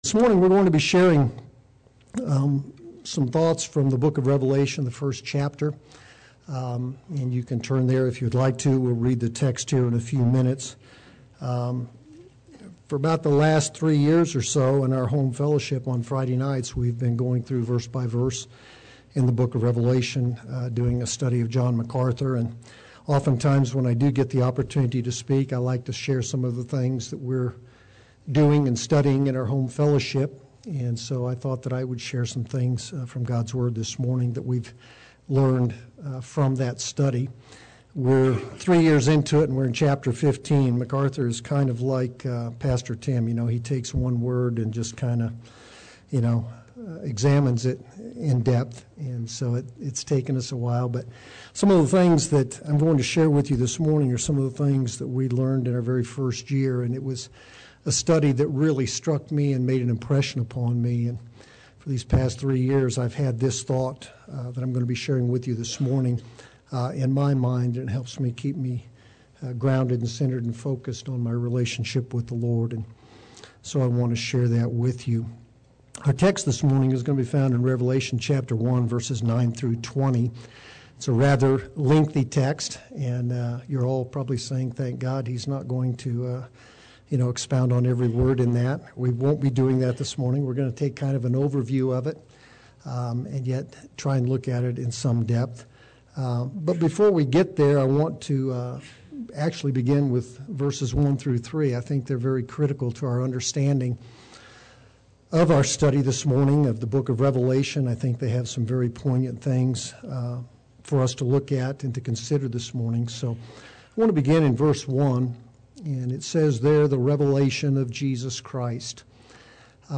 Play Sermon Get HCF Teaching Automatically.
The Lord of the Church Sunday Worship